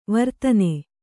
♪ vartane